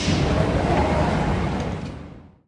sfx_train_arrive.ogg